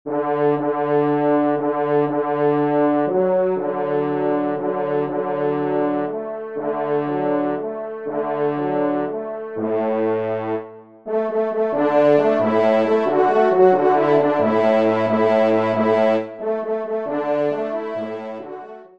Pupitre   4°Trompe